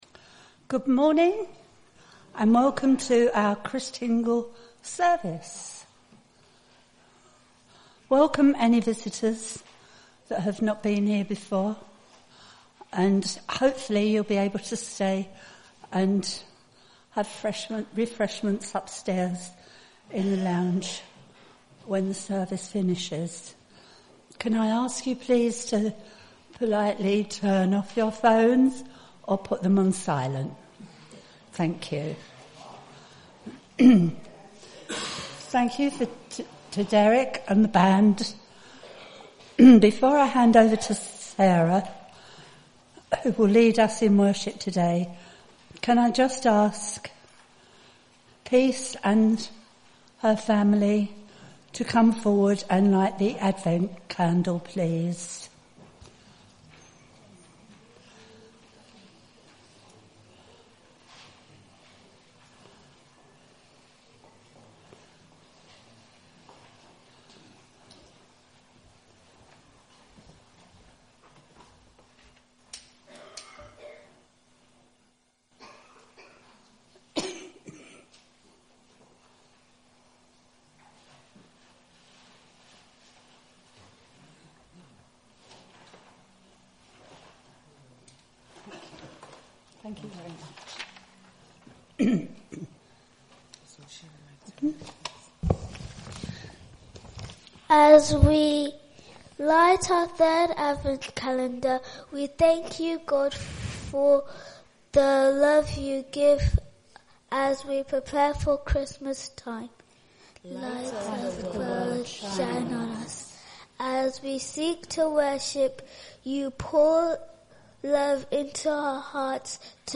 Those of the congregation who wish to, go out to the hall part way through the service to make Christingles then bring them back – with enough for everyone else. We then light them and sing ‘The Christingle Song’.
An audio version of the service is also availabe.